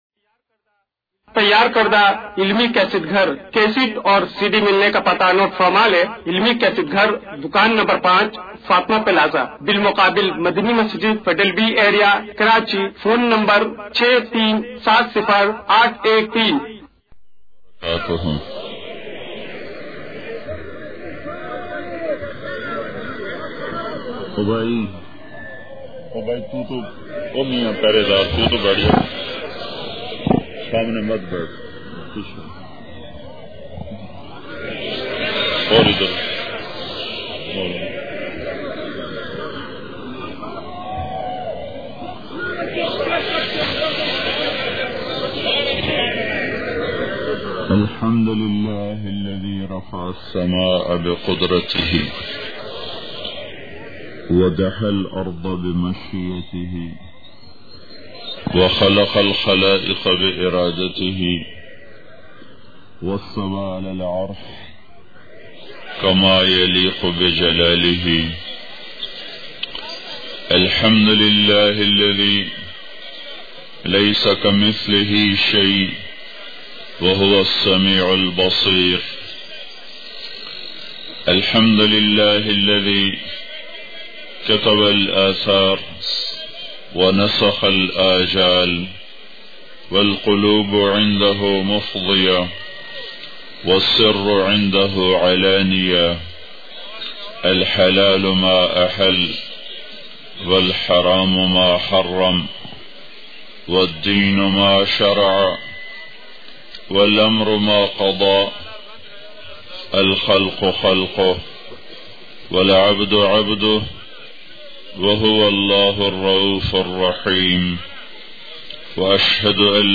ALLAH KI TALWAR SAWABI part1 bayan mp3